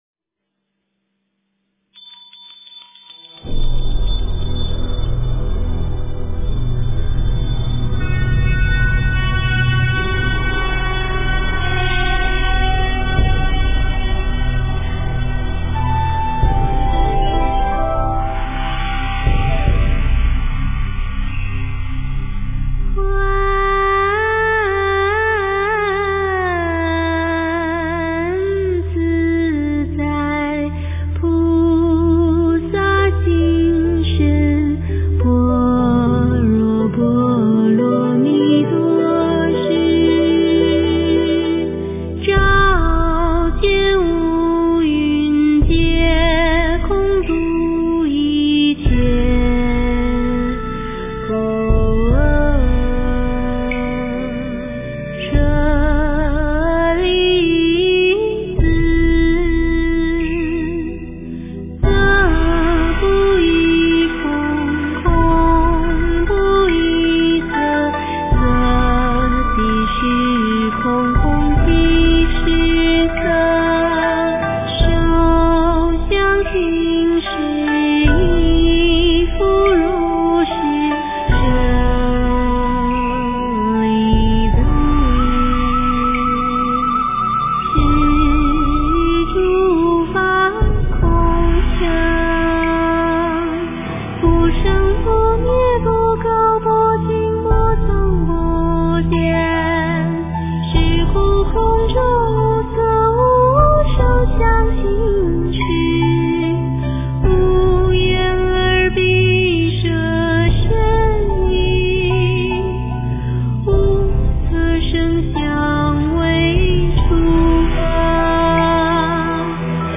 诵经
佛音 诵经 佛教音乐 返回列表 上一篇： 心经 下一篇： 大悲咒 相关文章 观音圣号《心经》 观音圣号《心经》--佛经音乐...